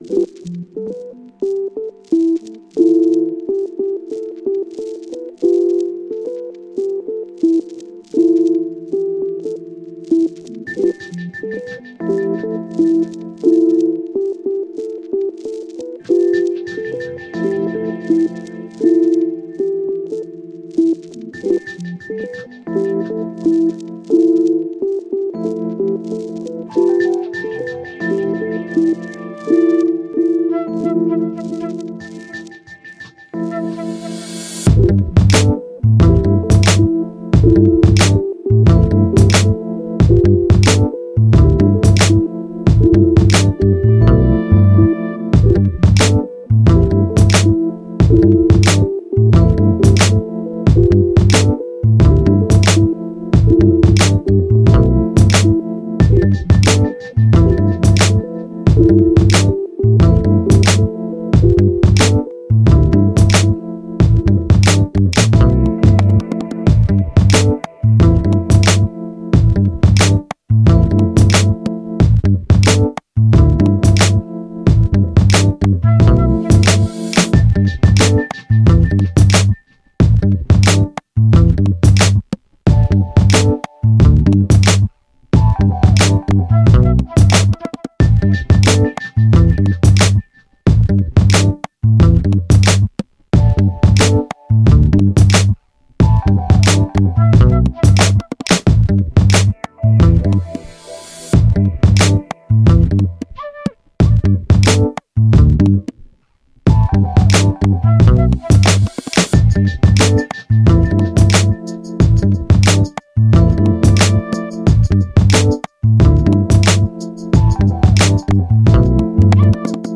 Am I Broken? (инструментальный хип-хоп собранный из кусочков и звуков)